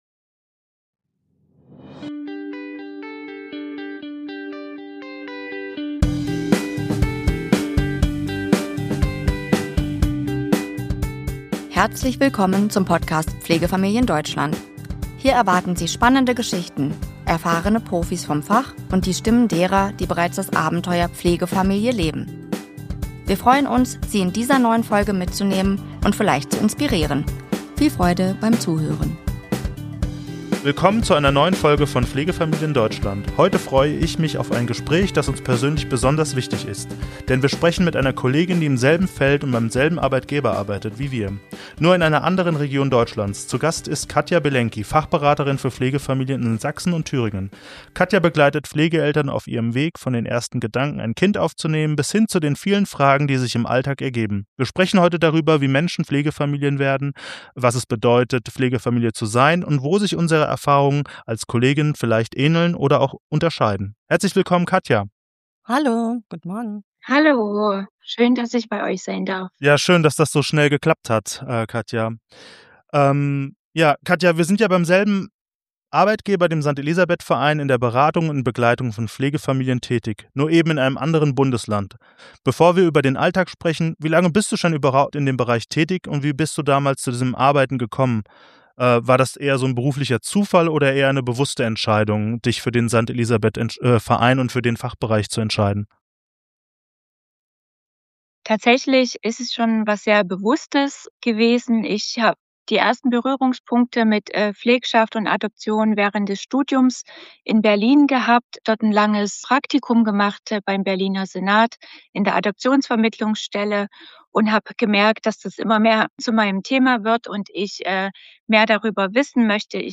Willkommen zu einer neuen Folge von Pflegefamilien Deutschland. Heute freue ich mich auf ein Gespräch, das uns persönlich besonders wichtig ist, denn wir sprechen mit einer Kollegin, die im selben Feld und beim selben Arbeitgeber arbeitet wie wir, nur in einer anderen Region Deutschlands.